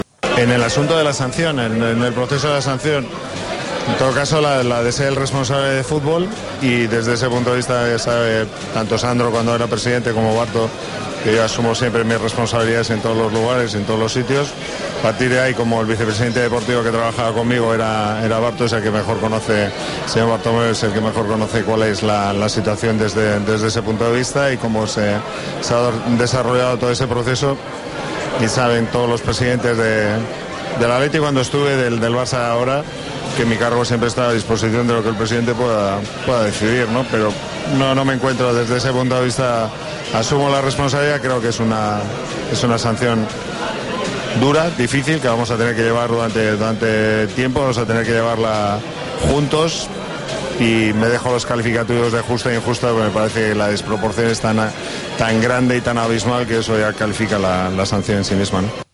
Este tema lo conoce más el señor Bartomeu, que era el vicepresidente deportivo en aquel momento”, dijo Zubi tras la derrota en Anoeta.